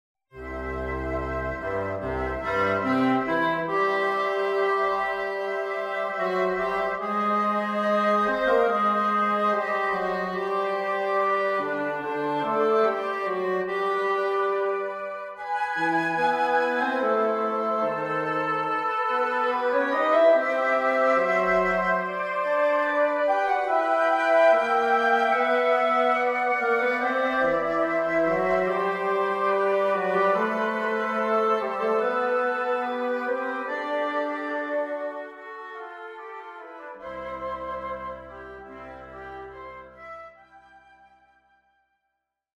I  Allegro moderato
Excerpt from First Movement (Woodwind)